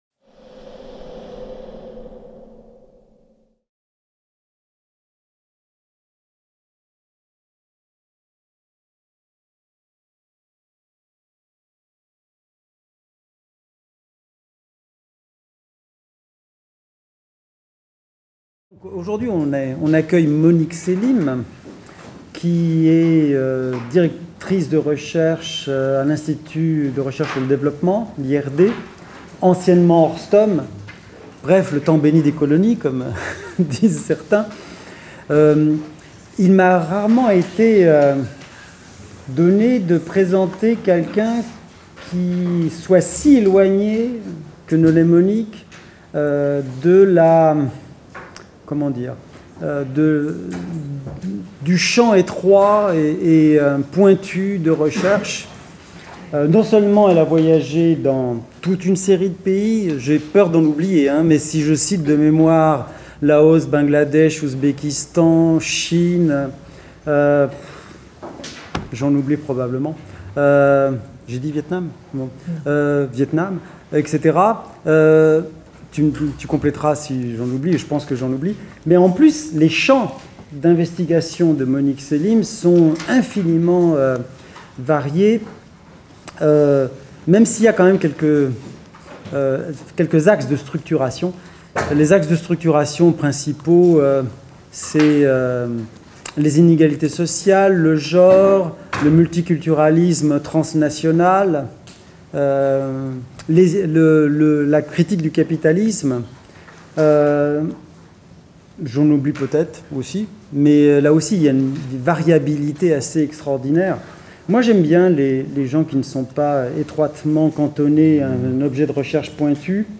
Séminaire du Master de sociologie